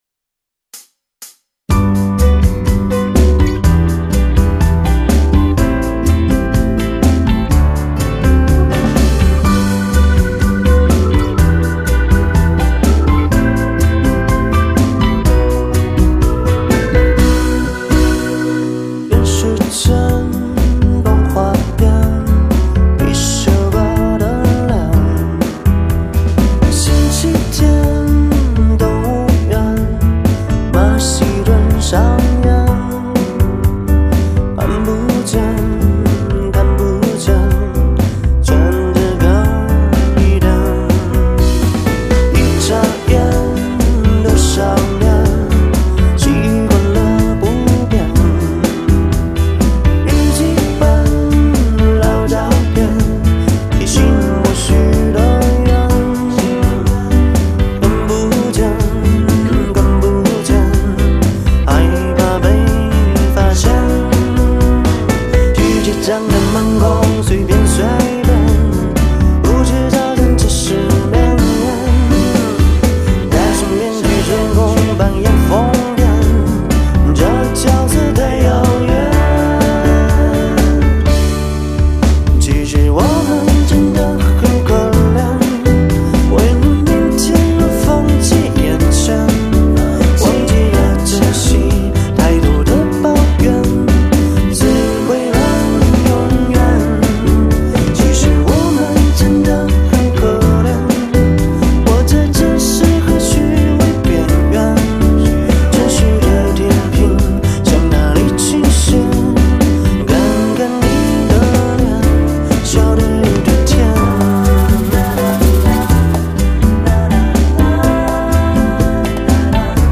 柔软却不失力道